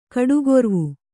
♪ kaḍugorvu